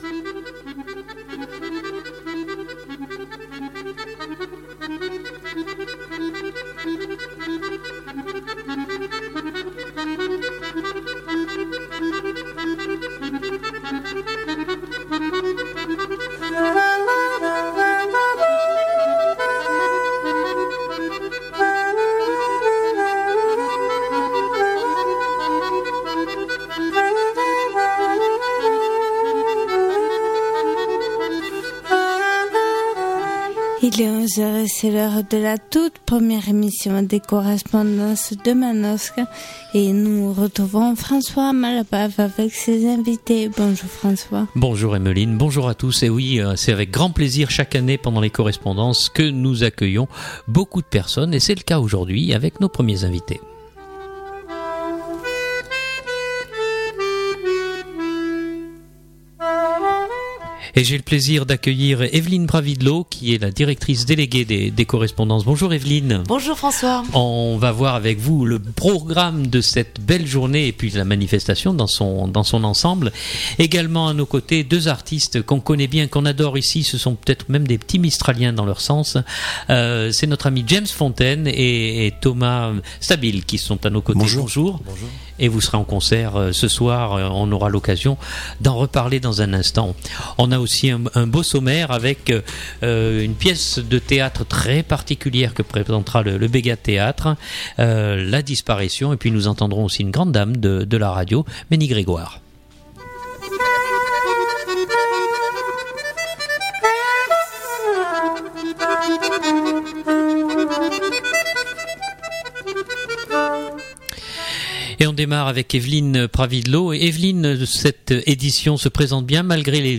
Puis toujours en 2008, Jeanne Bénameur commente à chaud l’interview de Mény.